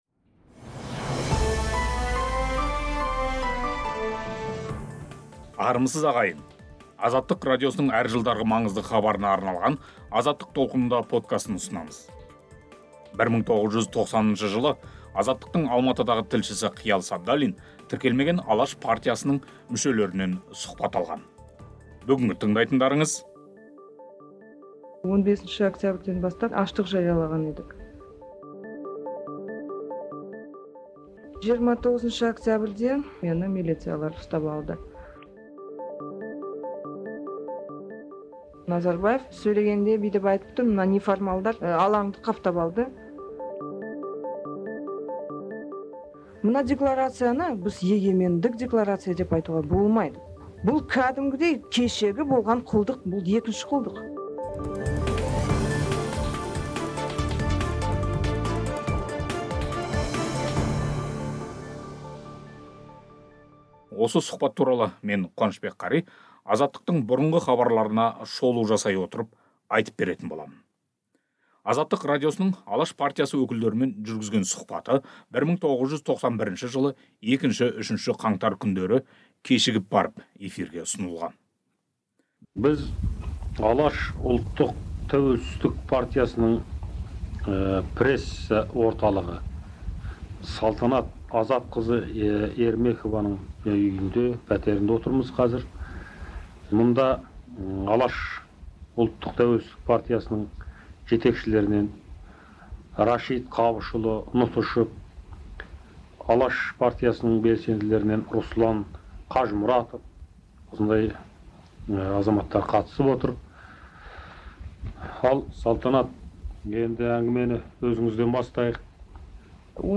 1990 жылғы 15 қазанда Қазақ ССР парламенті алдында аштық жариялаған "Алаш" партиясы белсенділері Азаттықа берген сұхбатта жаңарған одақтық шартқа қарсы болып, билікті тәуелсіздік алуға үндегенін және егемендік декларациясын неге құптамайтынын айтқан.